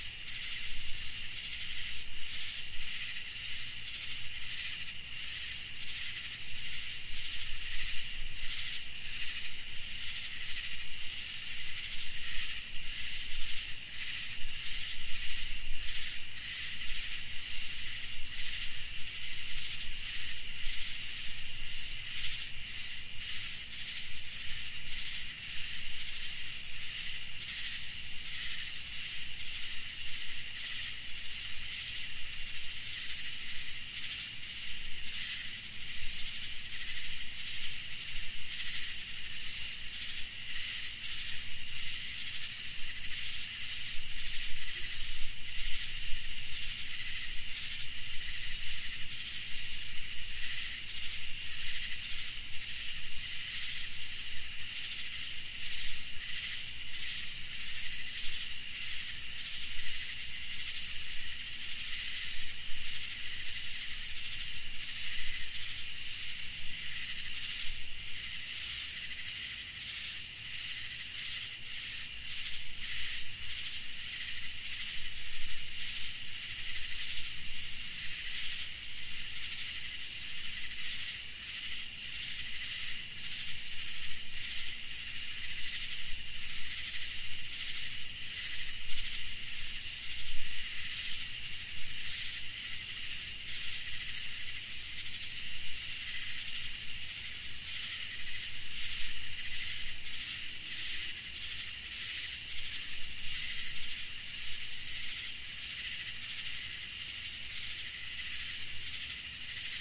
描述：一架飞机经过，渐渐消失，只留下蝉的声音/ un avion pasa y se extingue dejando solo el sonido de las chicharras
Tag: 飞机 现场记录 森林 昆虫 性质 夏天